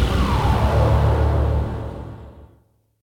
whirling.ogg